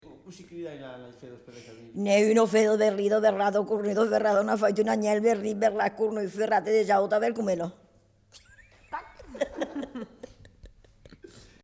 Aire culturelle : Lauragais
Genre : forme brève
Effectif : 1
Type de voix : voix de femme
Production du son : récité
Classification : virelangue